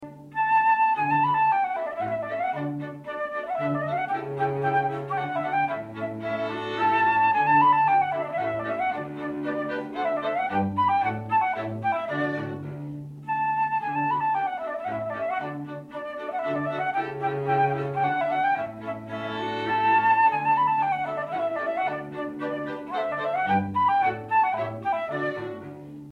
chamber music repertoire for wind and string instruments